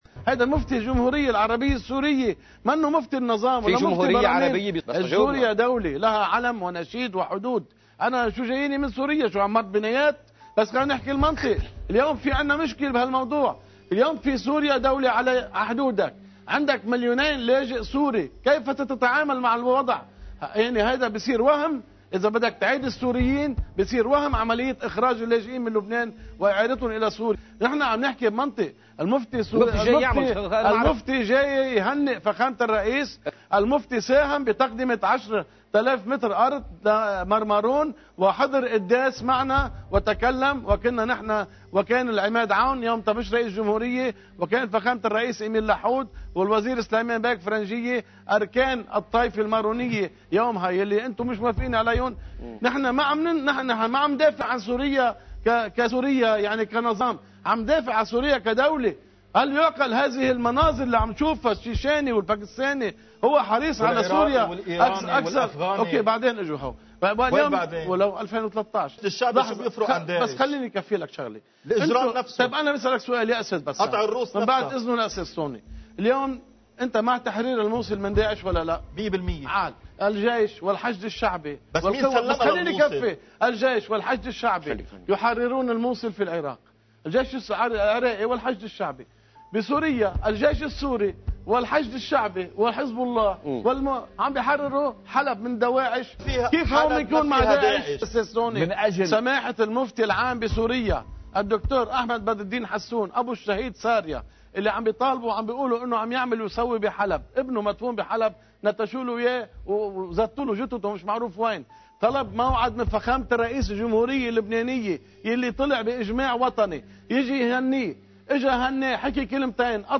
مقتطفات من حديث الكاتب والمحلل السياسي